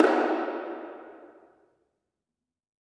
JJPercussion (264).wav